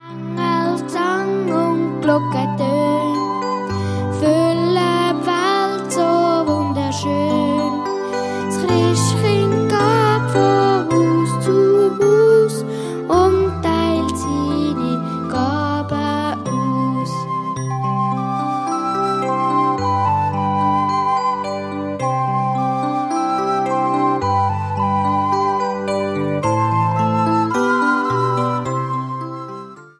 Alte Schweizer Weihnachtslieder sanft renoviert